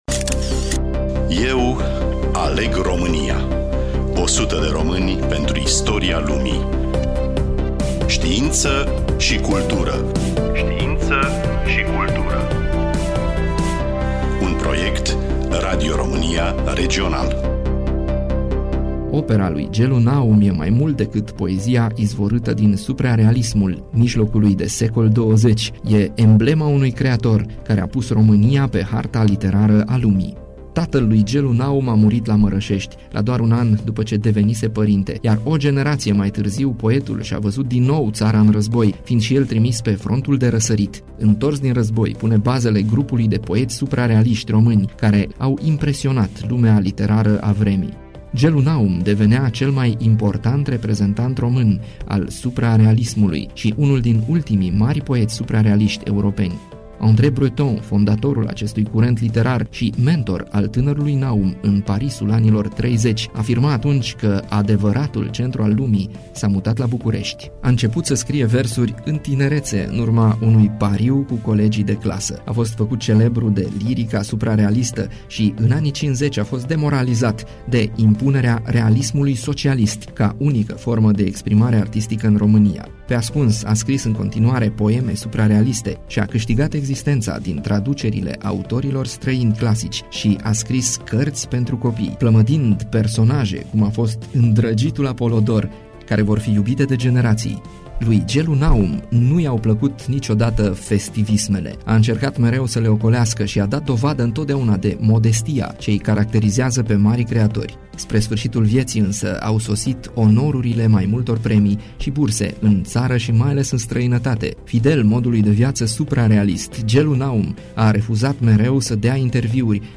Studioul Radio Timișoara